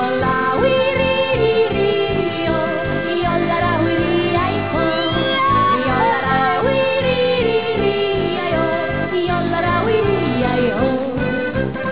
yodel.au